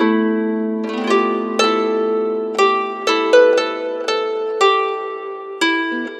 Harp12_116_G.wav